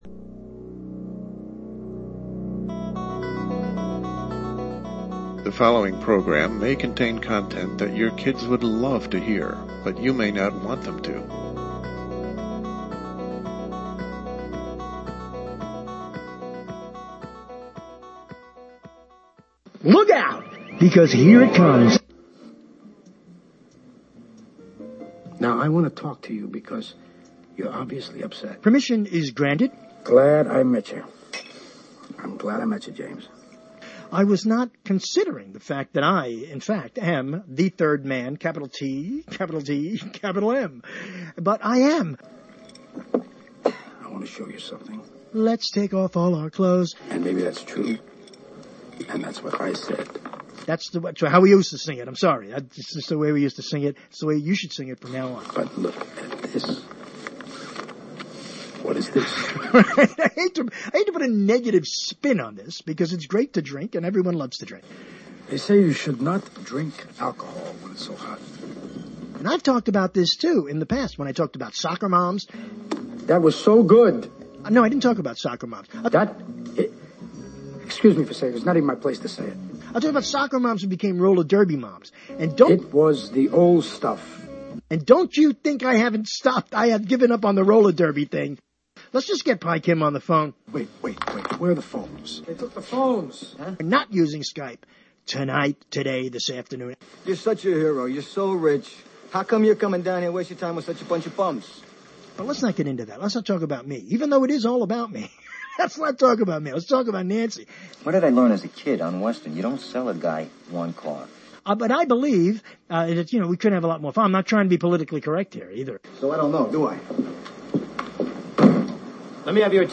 LIVE, Thursday, Dec. 5, LIVE at 9 p.m. — The best books of 2019, according to major reviewer sources, launches a solo show that is bound (no pun intended but all right if you want it to be a pun) to spin off from books to this and that and the other things. The internet’s original talk show continues in its original time slot with unmatched energy in the realm of broadcasting it created.